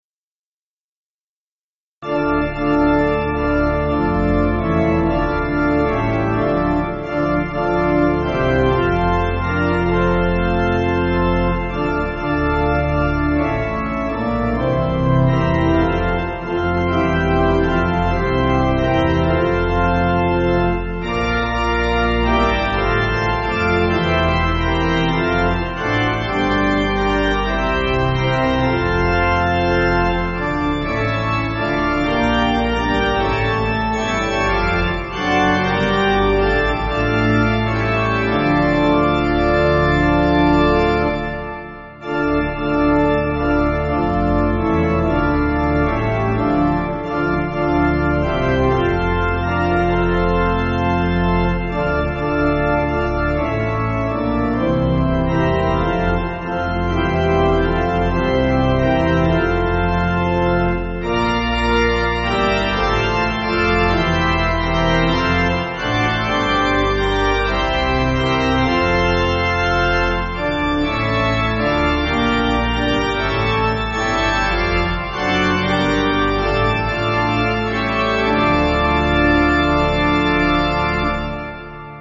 Organ
(CM)   3/Dm